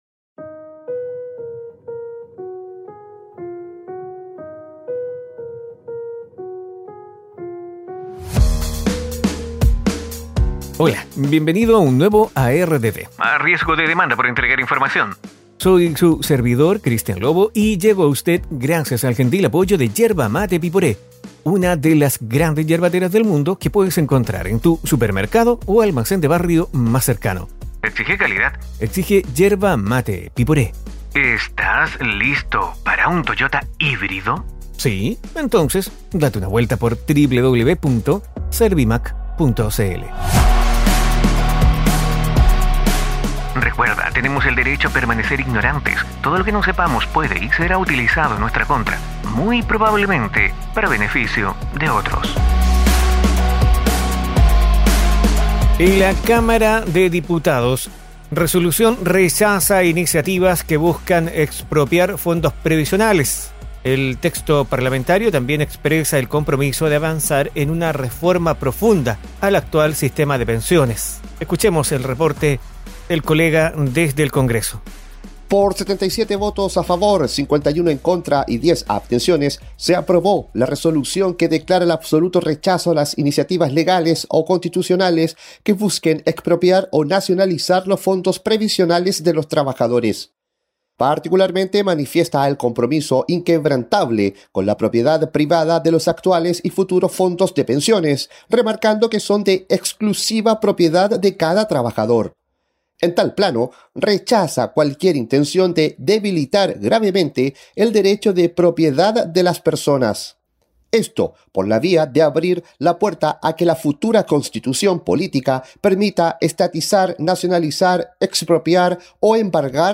proyecto del Gobierno y mociones de diputados se votarán juntos super lunes" Conversación con Patricio Vallespin, Gobernador de la Región de Los Lagos